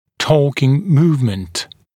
[‘tɔːkɪŋ ‘muːvmənt][‘то:кин ‘му:вмэнт]перемещение с применением торка